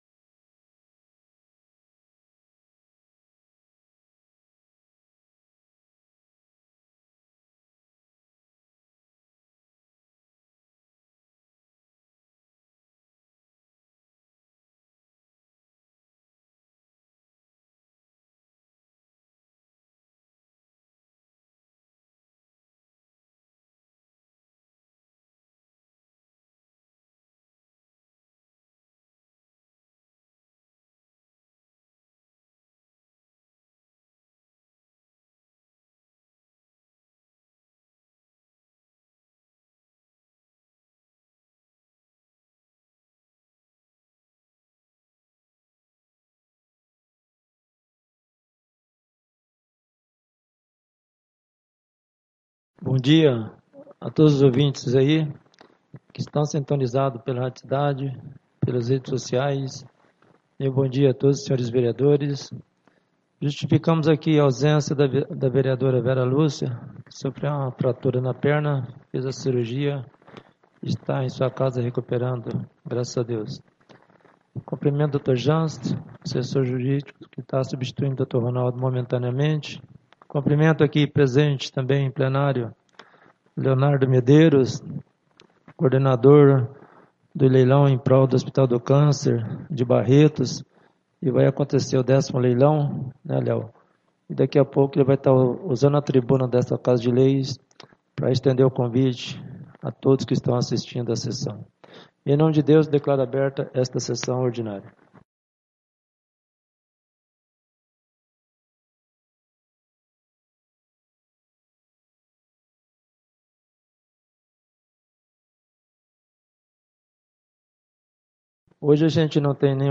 5° SESSÃO ORDINÁRIA DE 06 DE ABRIL DE 2026